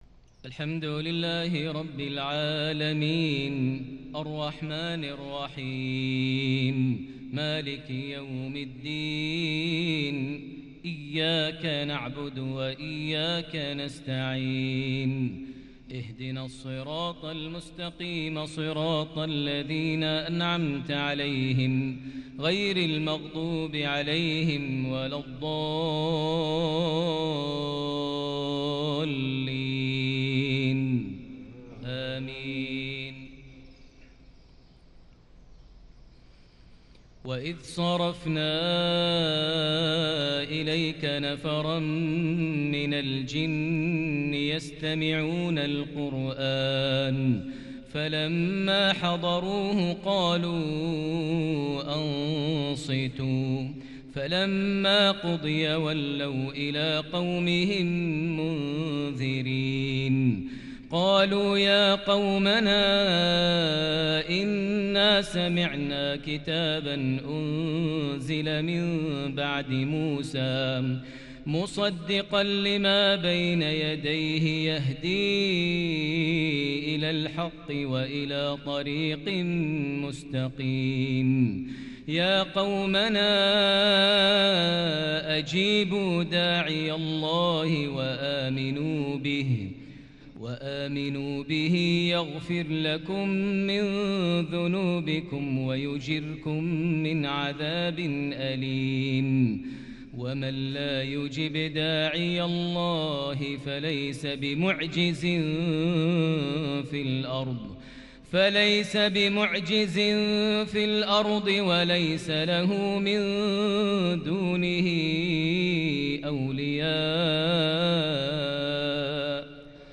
مغربية فريدة بالكرد العذب لخواتيم سورة الأحقاف | 2 شعبان 1442هـ > 1442 هـ > الفروض - تلاوات ماهر المعيقلي